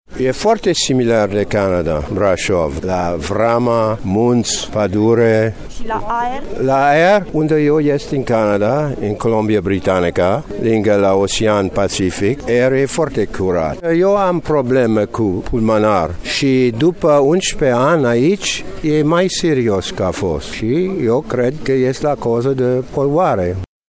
Chiar și așa, la eveniment a fost prezent un cetățean canadian, care de 11 ani locuiește în Brașov: